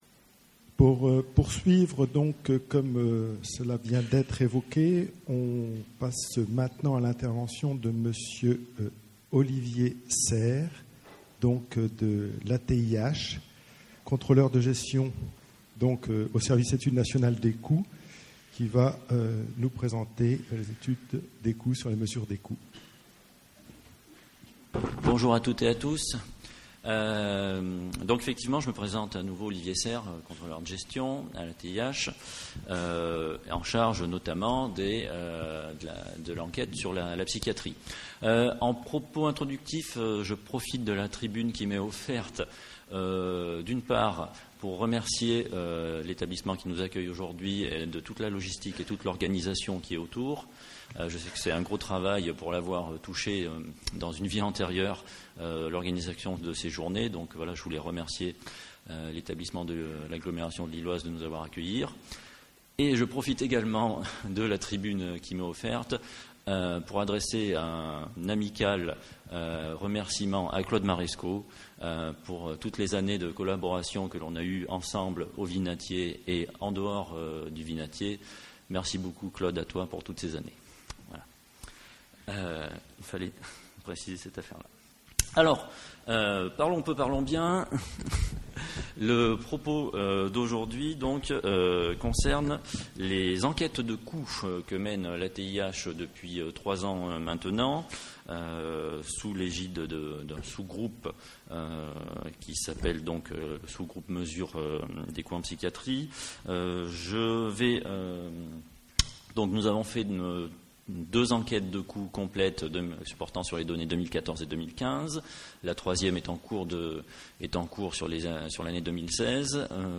Les 6èmes JIMCGPSY se sont tenues les 21 et 22 septembre 2017 à Saint-André-lez-Lille.